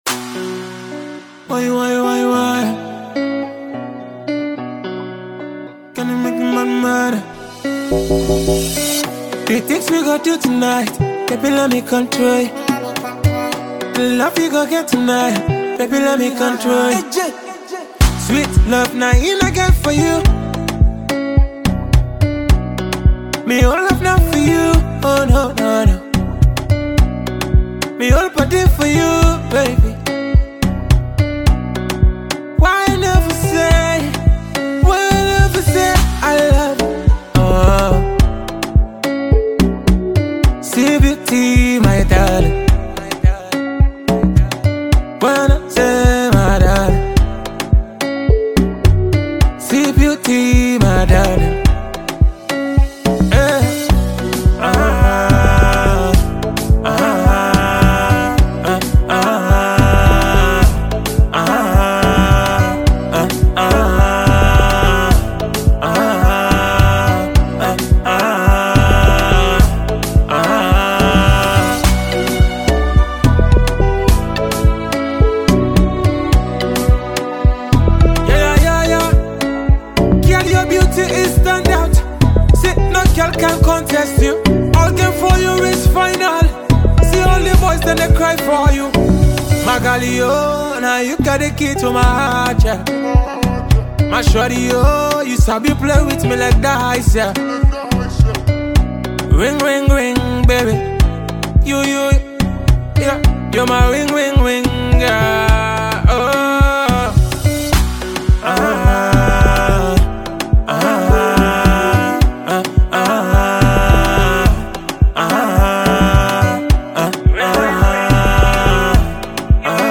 Afrobeats love song